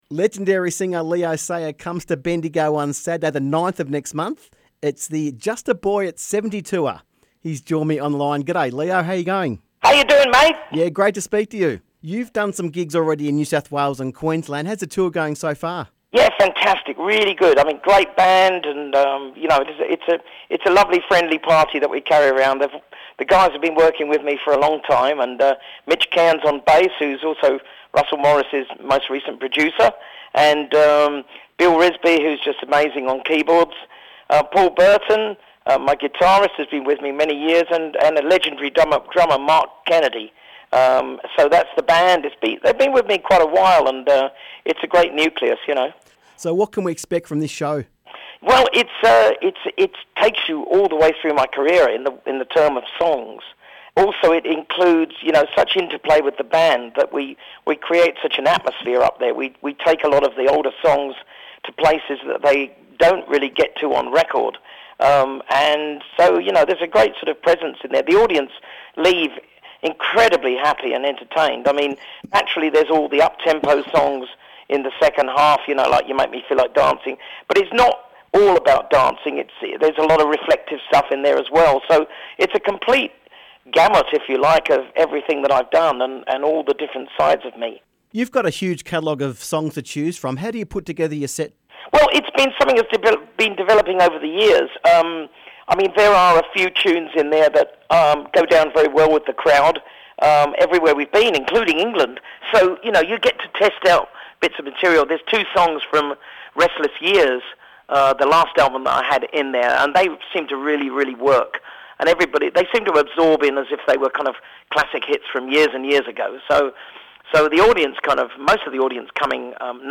Leo Sayer Interview 23/02/19